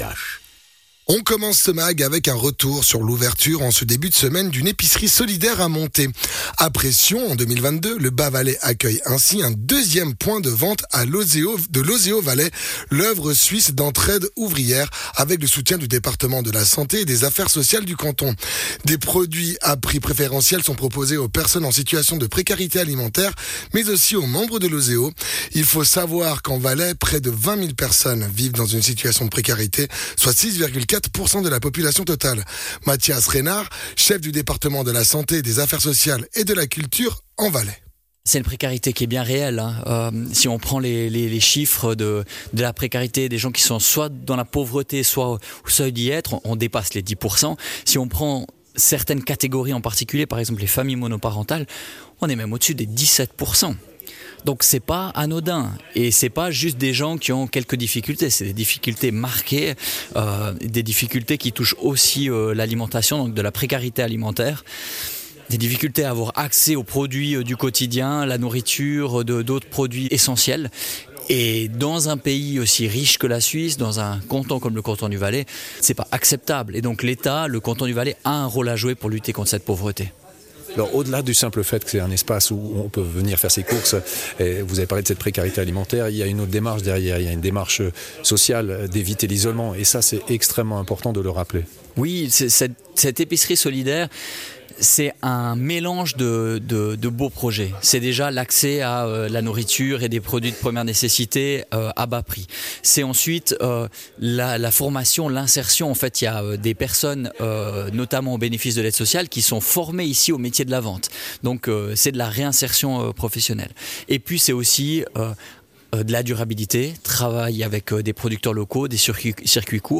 Intervenant(e) : Mathias Reynard, Chef du Département de la santé, des affaires sociales et de la culture en Valais.